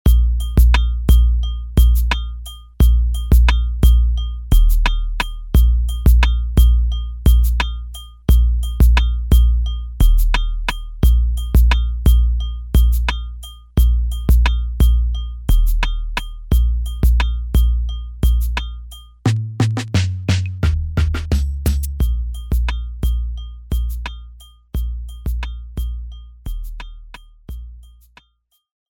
West Coast Rap Beats